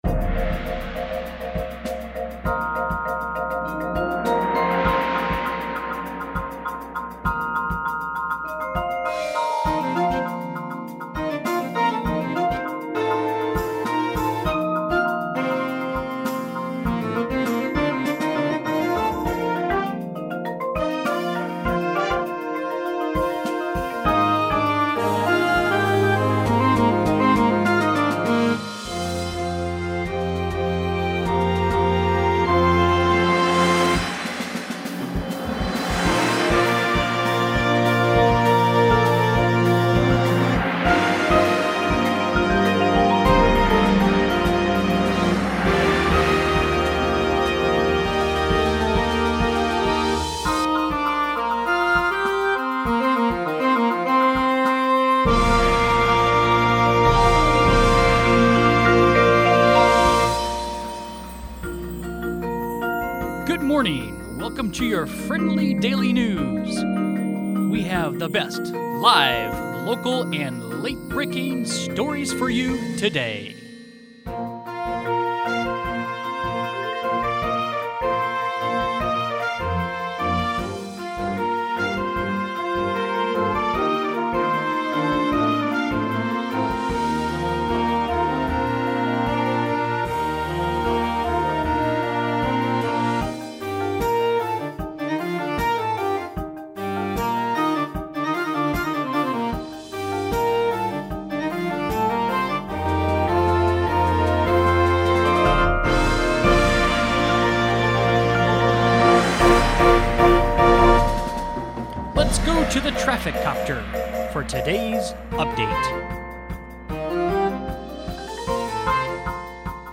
• Front Ensemble with Drum Set